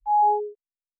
Digital Click 13.wav